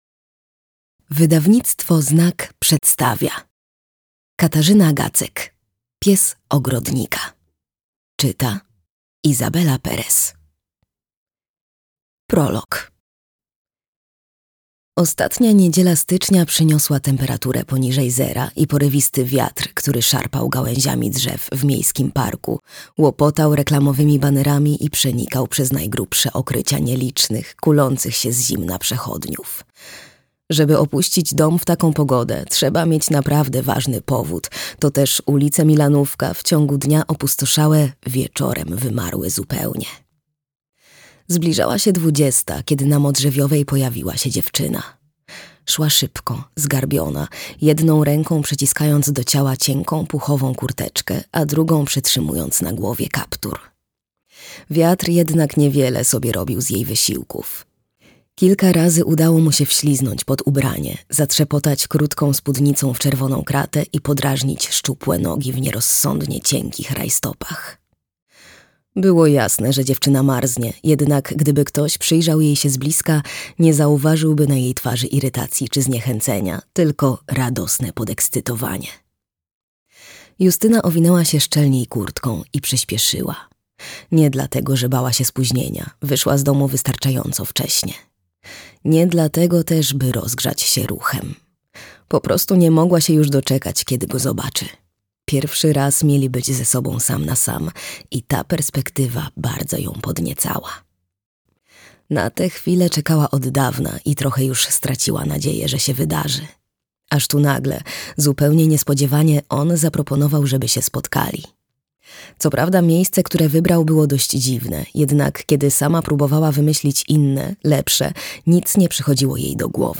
Pies ogrodnika. Agencja Detektywistyczna CZAJKA - Katarzyna Gacek - audiobook